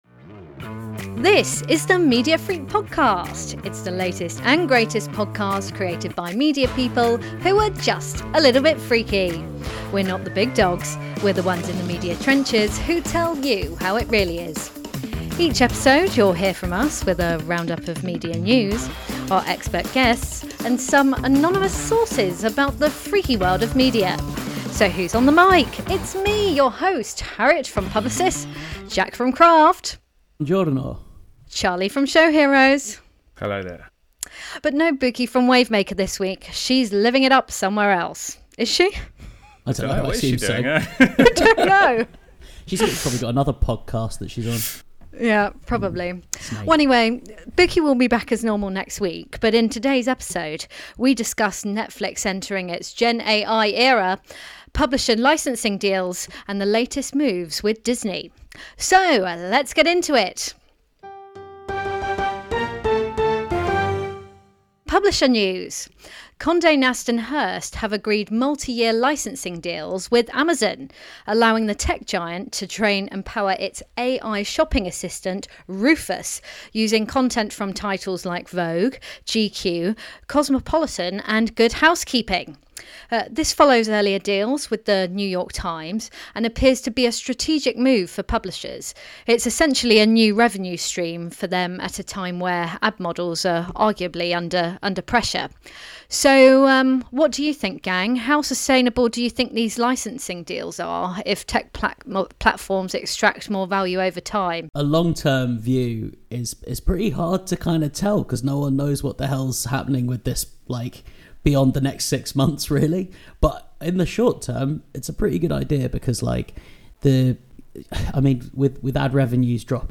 Welcome to the marketing and advertising podcast presented by a team from across the industry.